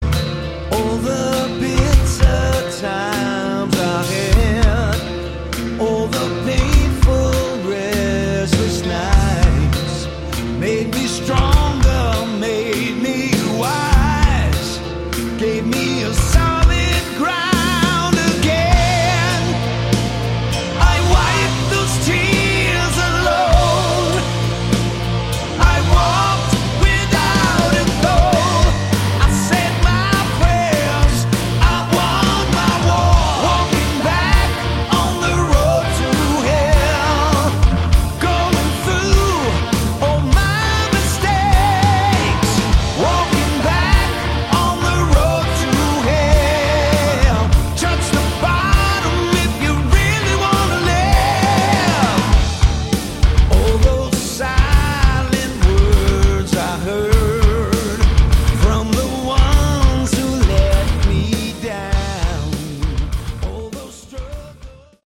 Category: AOR / Melodic Hard Rock
vocals
guitar
keyboards
bass
drums